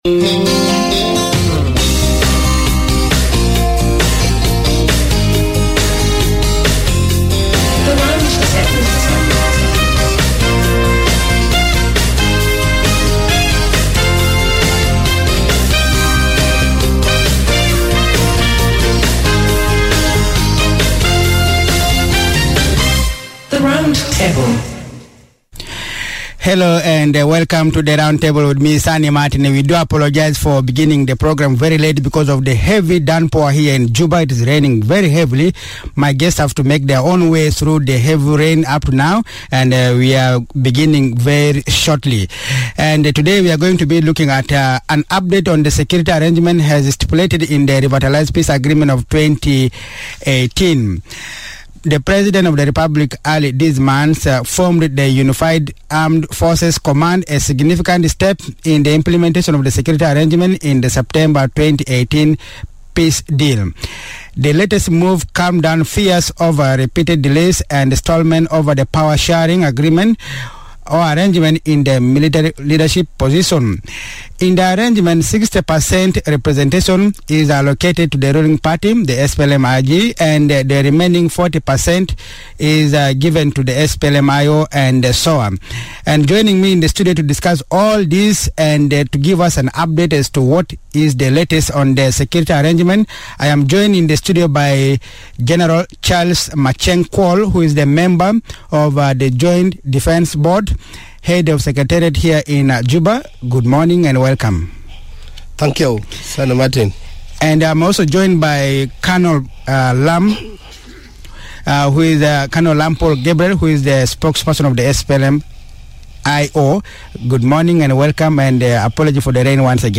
Round Table - Discussion of Unified Command Part One